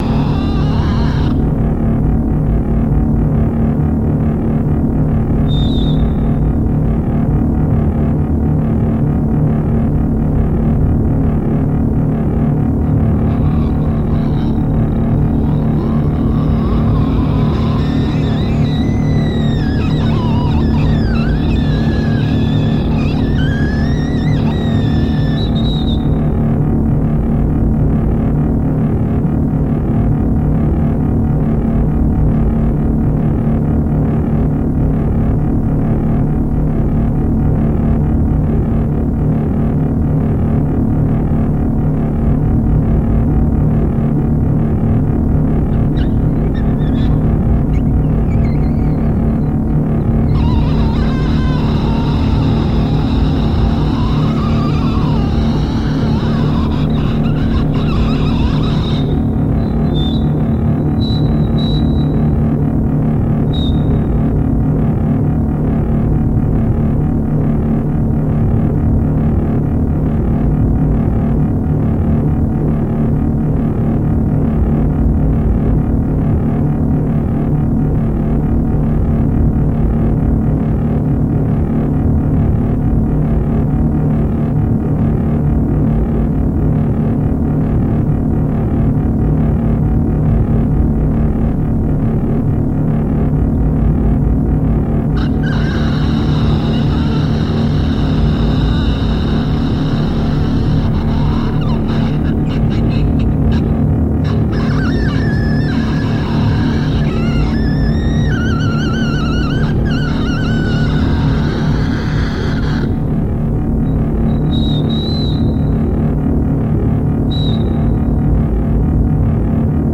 Recorded March 1997 in Sassuolo, Italy
raw, improvised
With its haunting vocalisations and abrasive soundscapes
• Genre: Death Industrial / Power Electronics